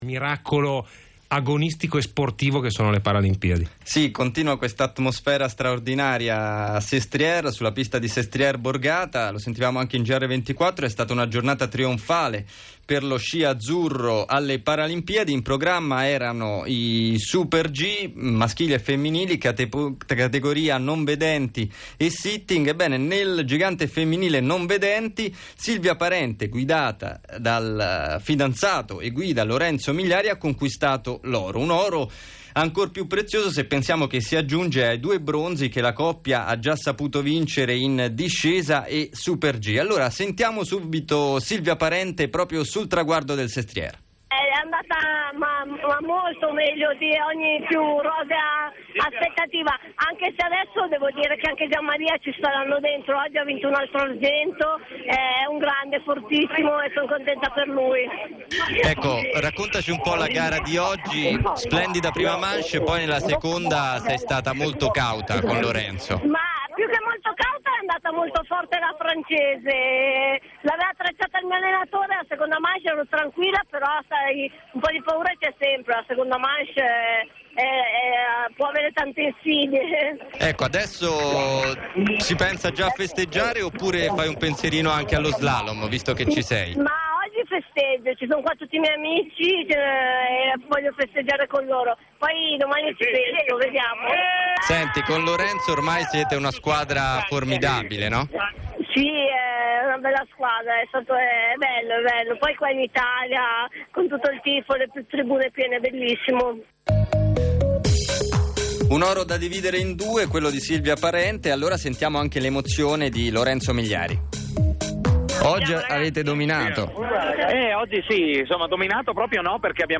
l'intervista